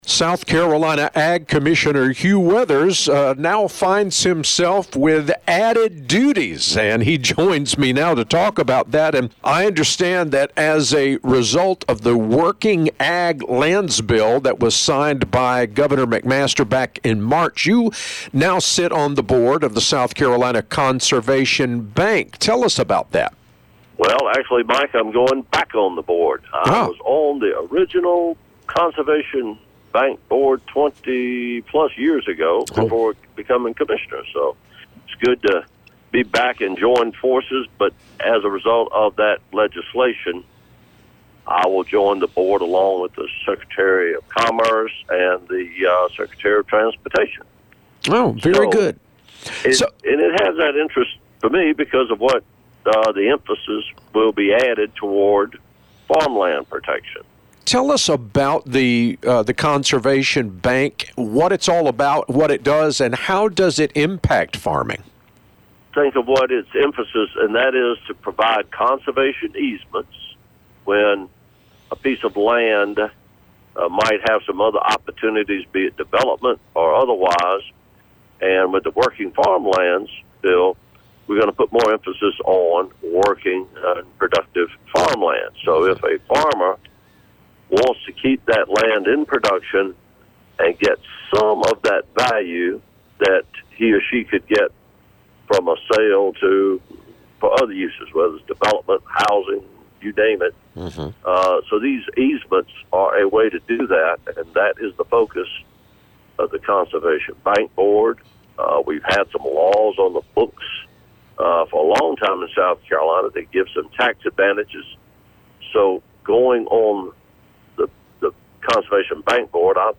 Every week Commissioner Hugh Weathers talks about what’s going on with agriculture in our state with The Southern Farm Network.
Check back each week to hear the latest news, or you can listen to past interviews here.